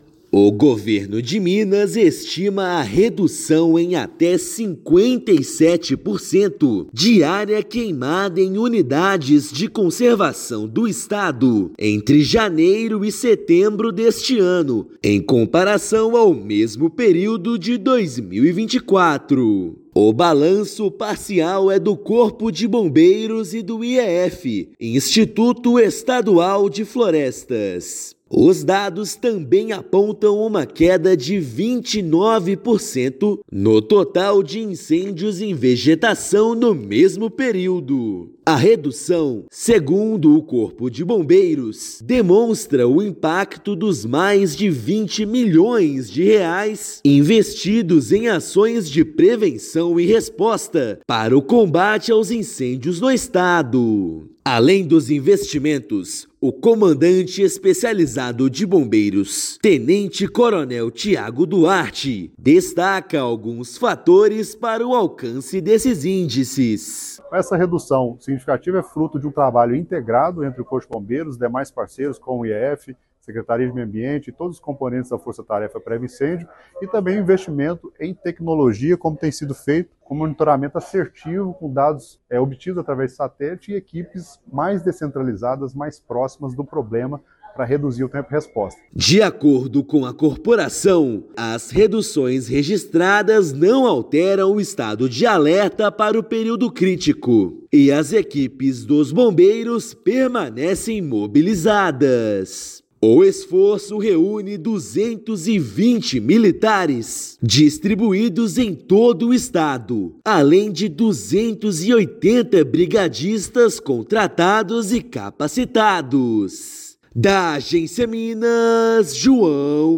Redução parcial nos incêndios não altera estado de atenção do Corpo de Bombeiros Militar para o período crítico. Ouça matéria de rádio.